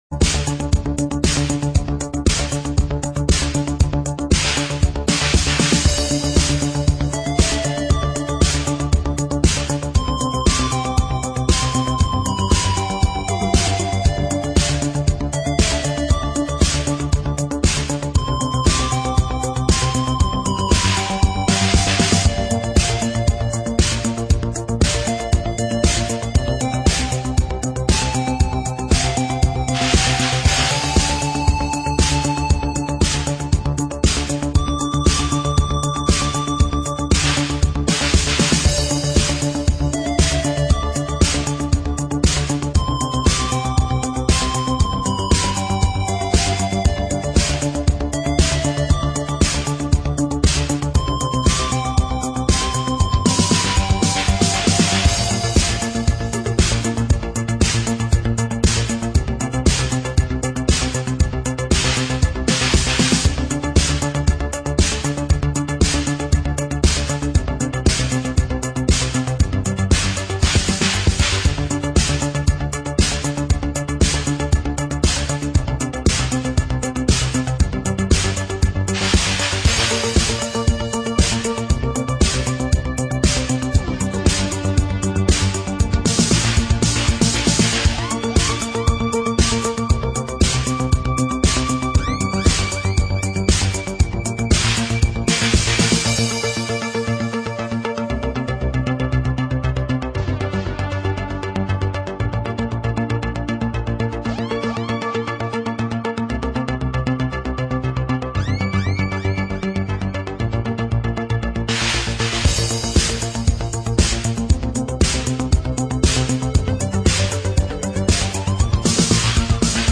レトロ・フューチャーなサウンドが最高です！